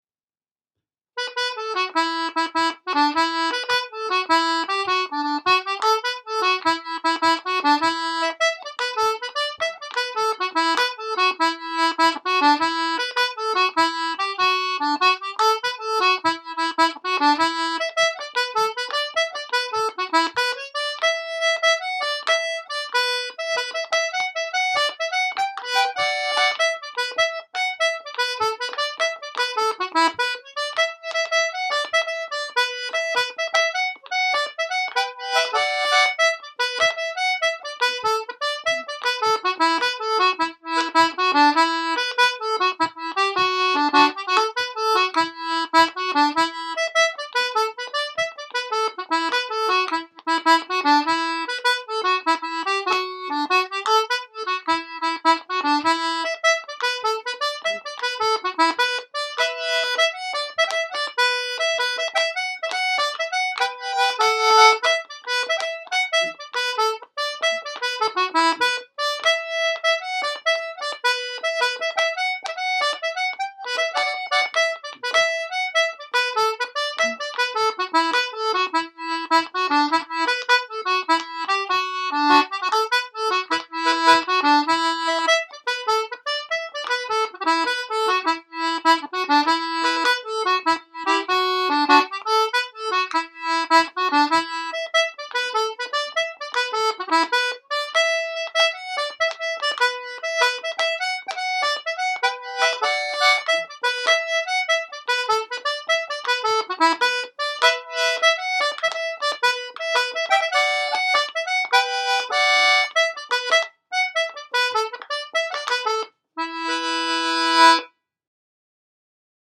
Winnie Hayes (100 bpm) – Sean-nós & Set Dance